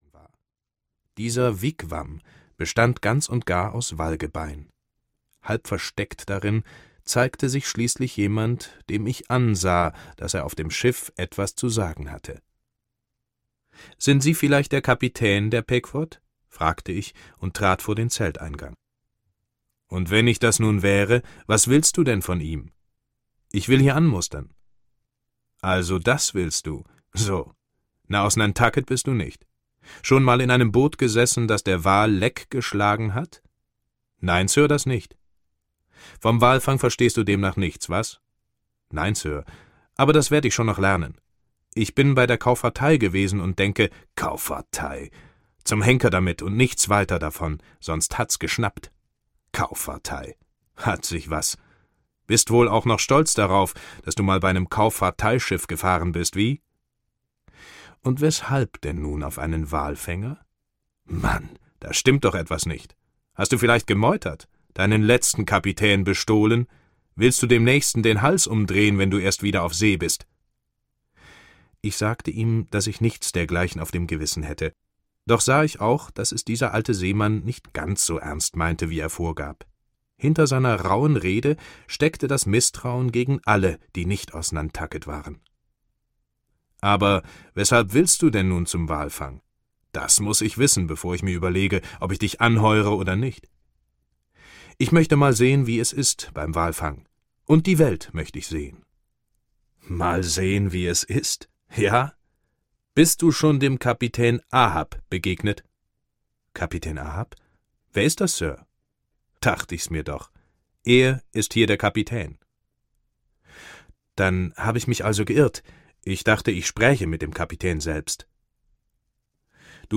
Moby Dick - Herman Melville - Hörbuch